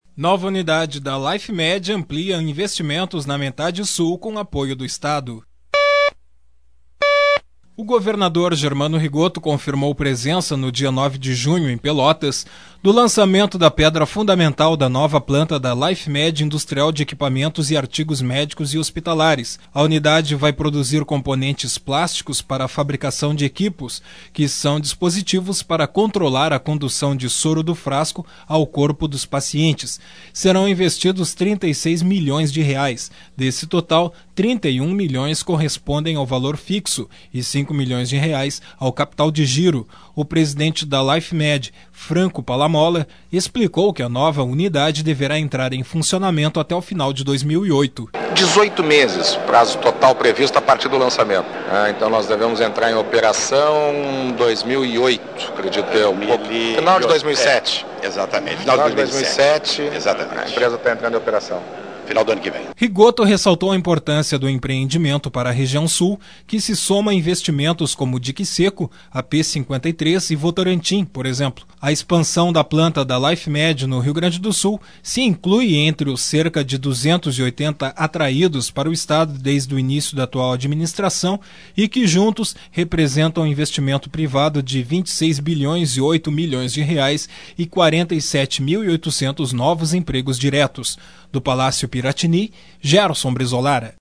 O governador Germano Rigotto confirmou presença, no dia 9 de junho, em Pelotas, do lançamento da pedra fundamental da nova planta da Lifemed. Sonora